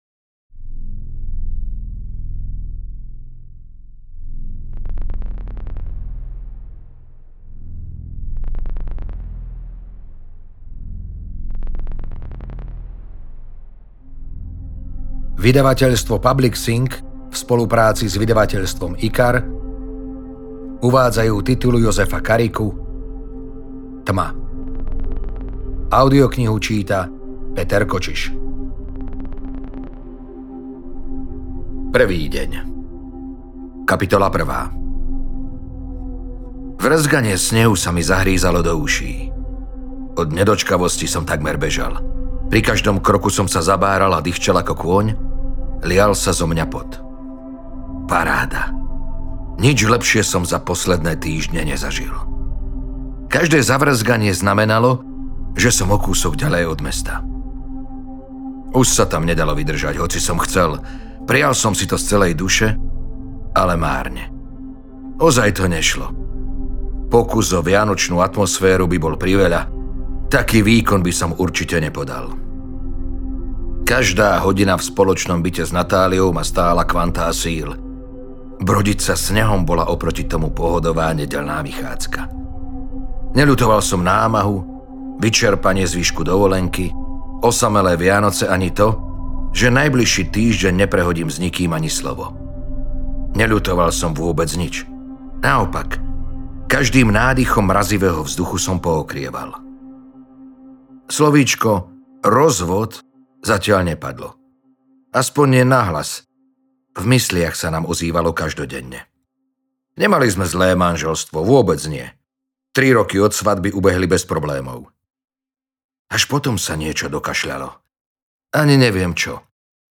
Tma audiokniha
Ukázka z knihy